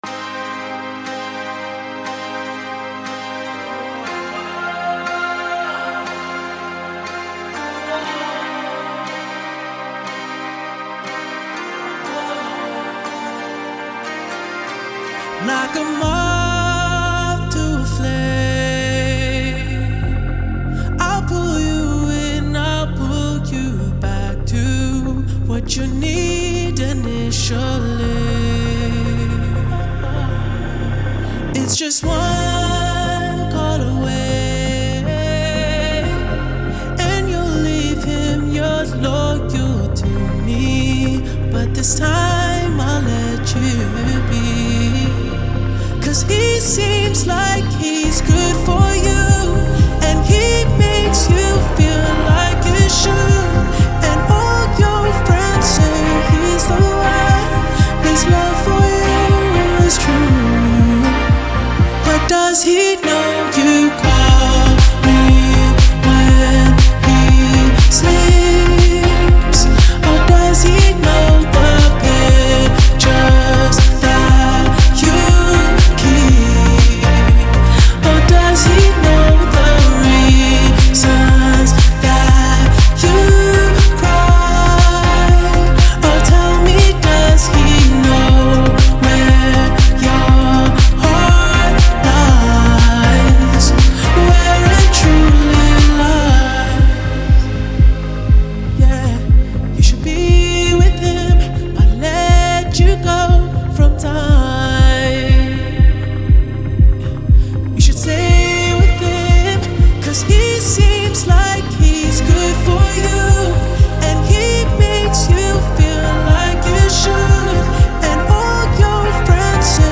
Genre : R&B